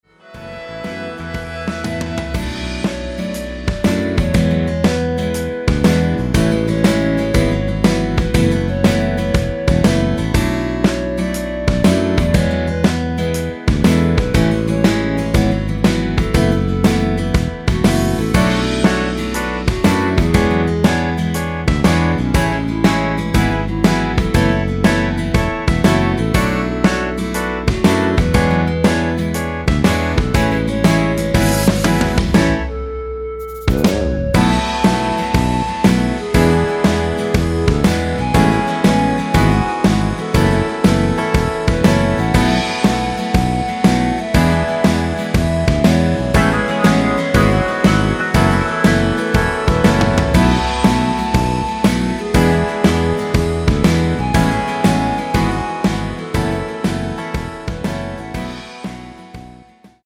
멜로디 MR이라고 합니다.
앞부분30초, 뒷부분30초씩 편집해서 올려 드리고 있습니다.
중간에 음이 끈어지고 다시 나오는 이유는